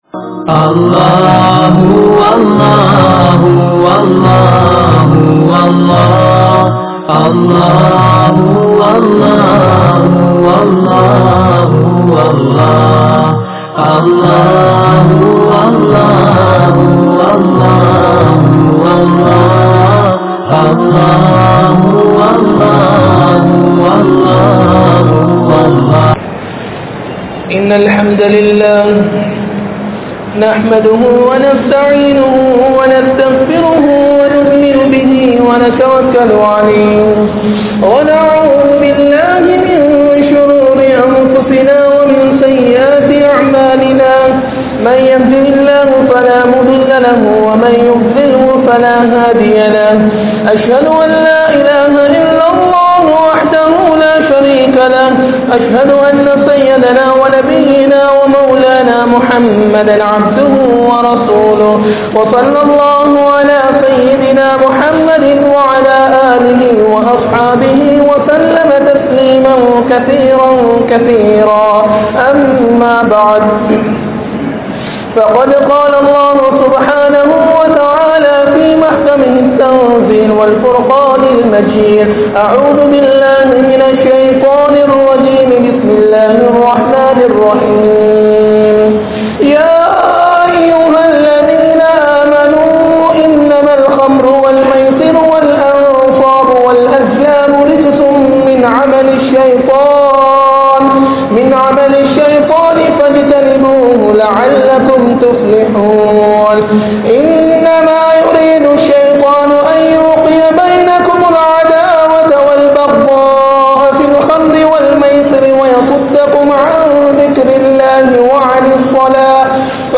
Samoohaththai Alikkum Boathai Vasthu (சமூகத்தை அழிக்கும் போதைவஸ்து) | Audio Bayans | All Ceylon Muslim Youth Community | Addalaichenai
Colombo 04, Majma Ul Khairah Jumua Masjith (Nimal Road)